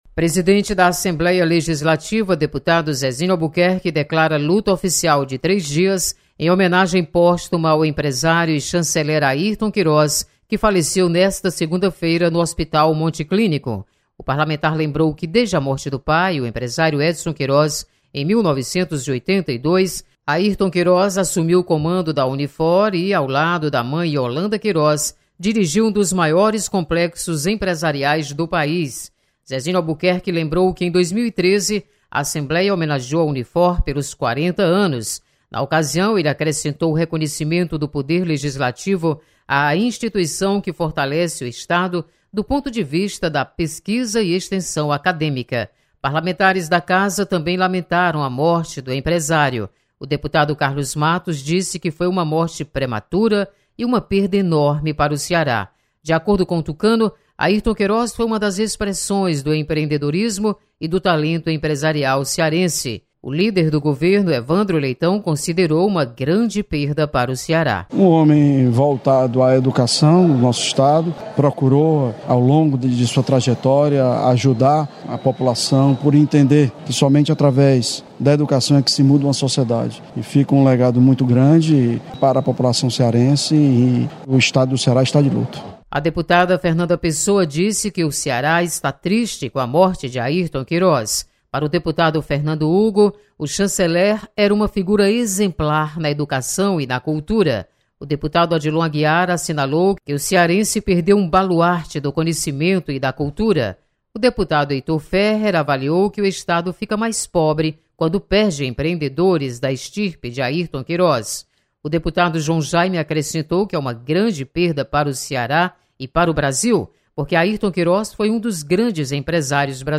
Você está aqui: Início Comunicação Rádio FM Assembleia Notícias Homenagem